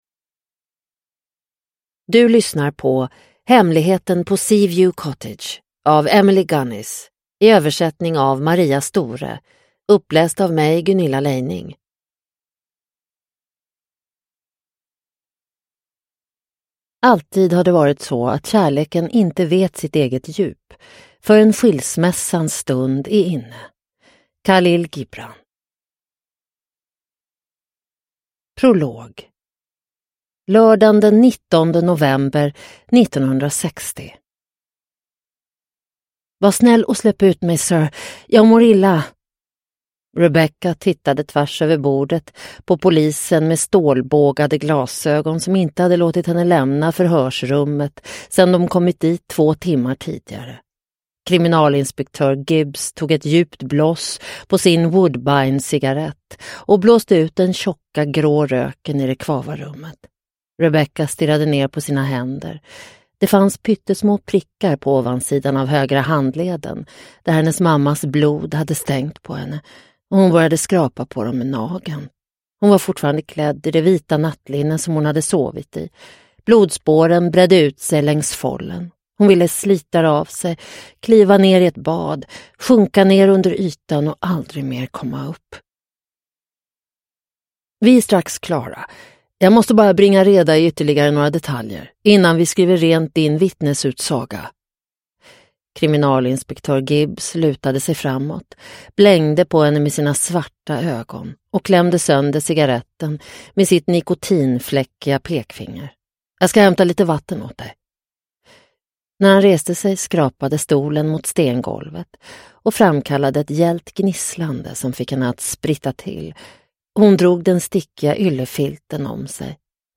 Hemligheten på Seaview Cottage (ljudbok) av Emily Gunnis